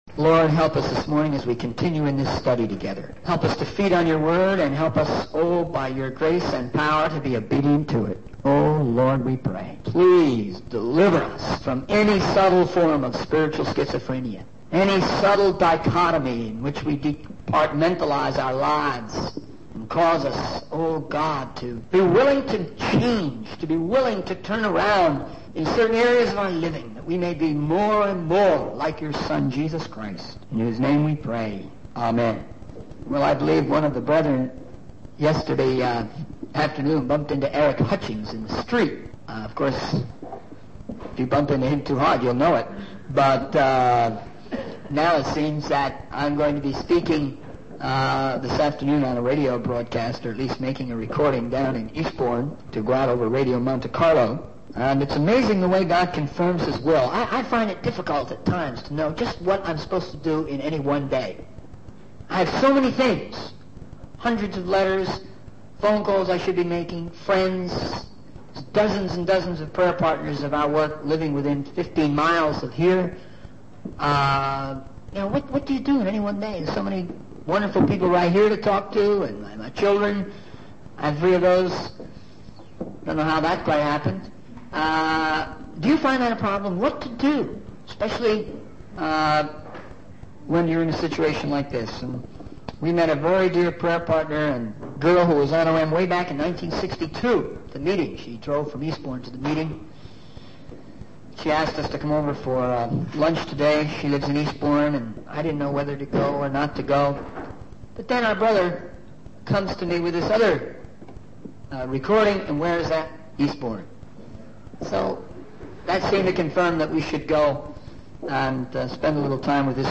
In this sermon, the speaker reflects on the challenges and perils faced by Christians in their journey of faith. He emphasizes the importance of staying committed to Christ despite the difficulties and temptations that may arise.